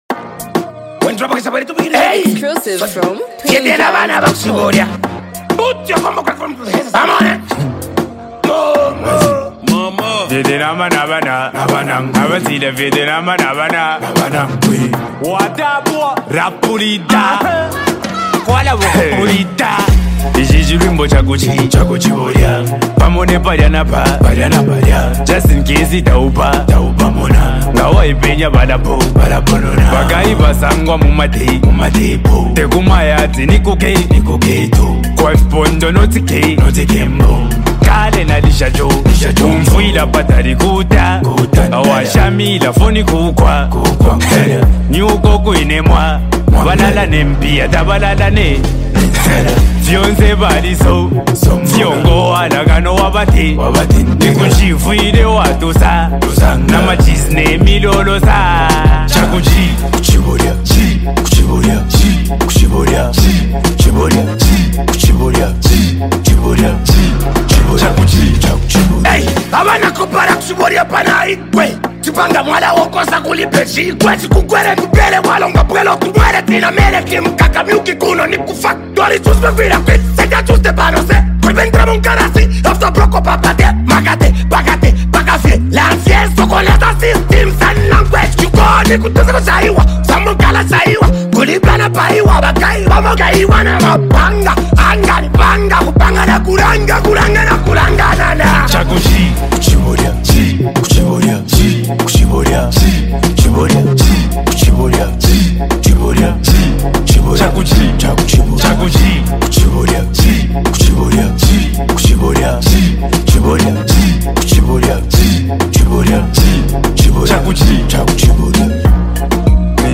Talented act and super creative rapper